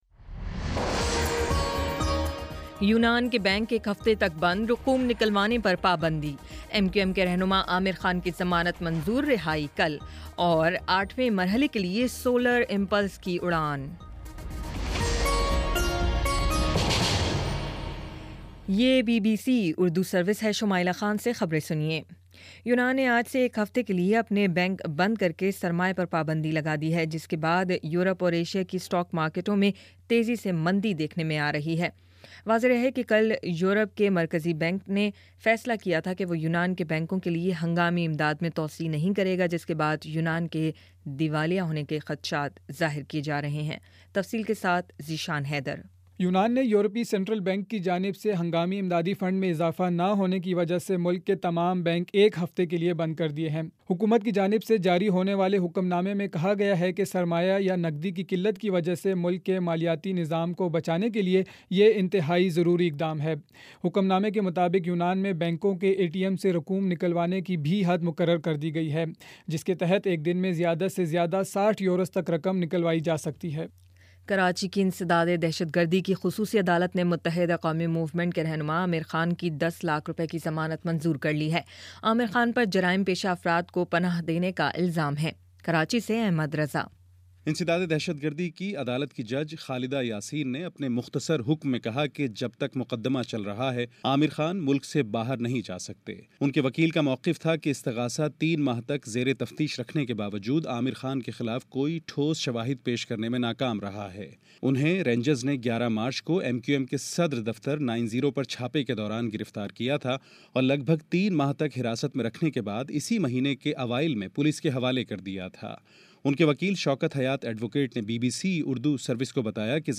جون 29: شام پانچ بجے کا نیوز بُلیٹن